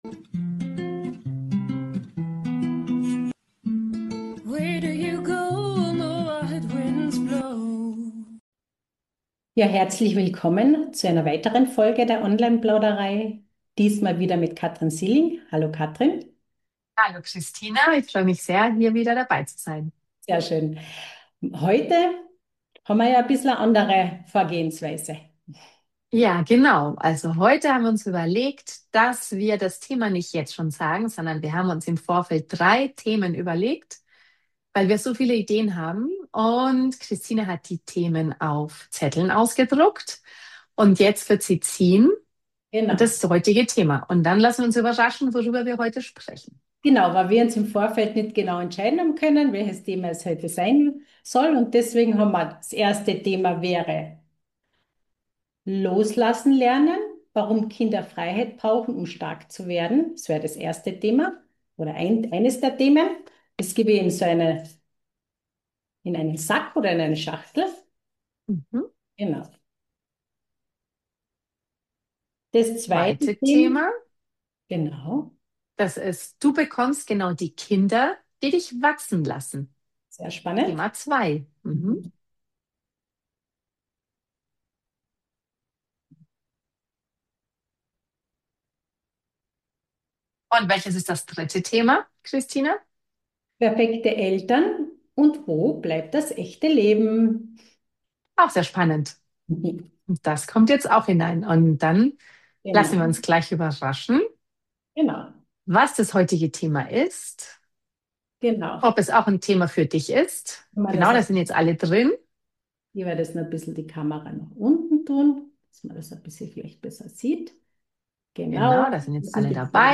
Und wie wir wieder zu mehr Leichtigkeit im Alltag finden. Genau darüber reden wir – offen, ehrlich und mit einer guten Portion Humor.